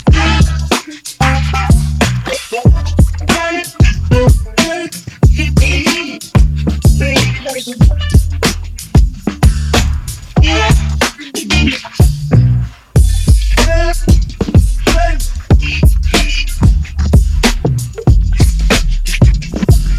musicgen small fine tuned on an edm dataset